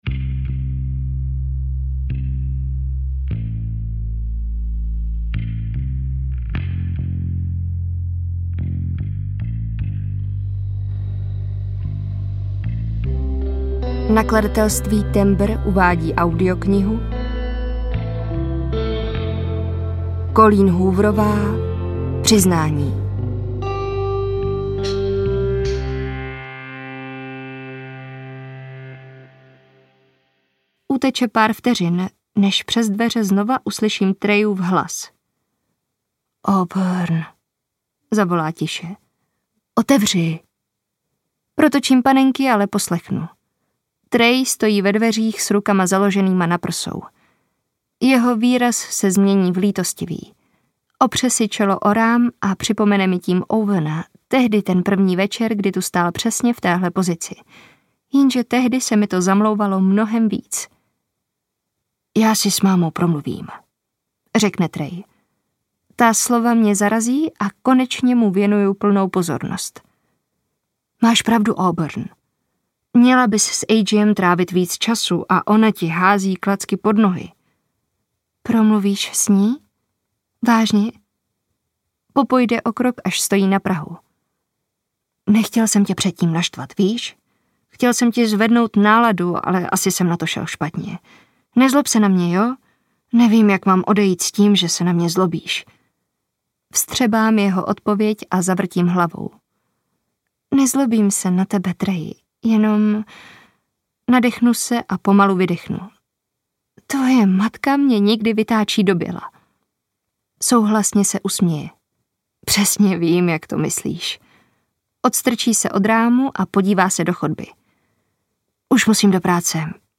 Přiznání audiokniha
Ukázka z knihy